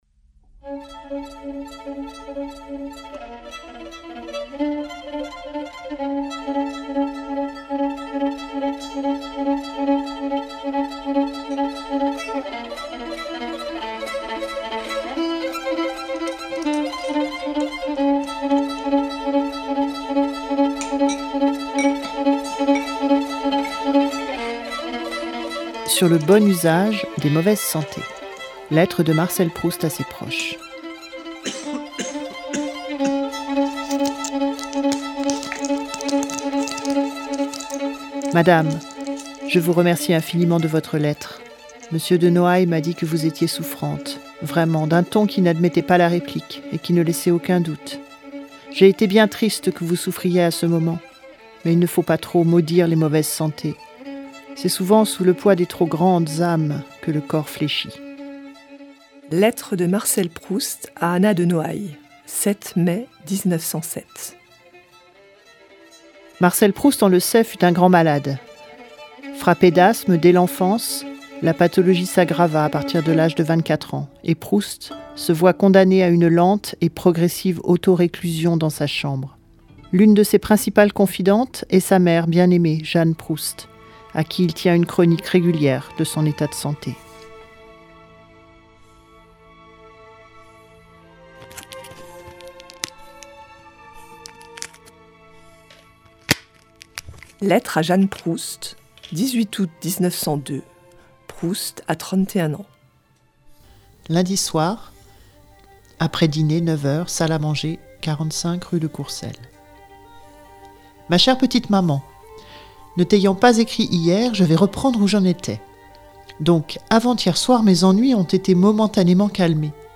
Les ateliers de fictions radiophoniques